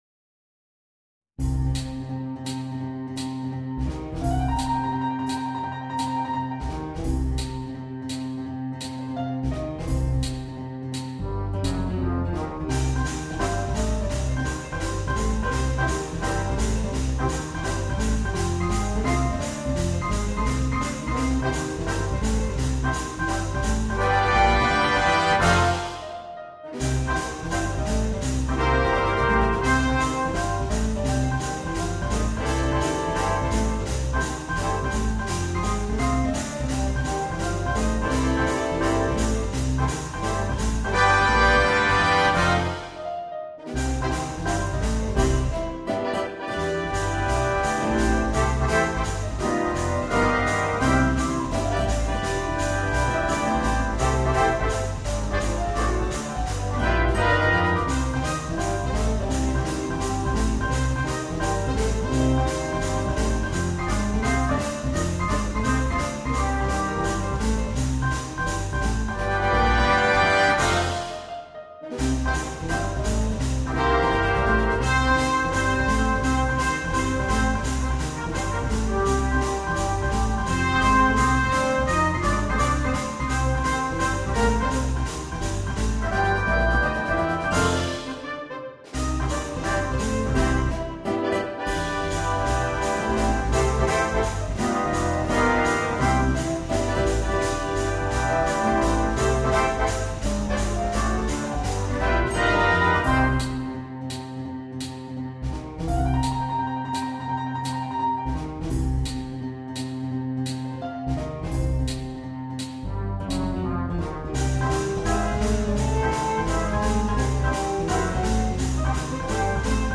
на биг-бэнд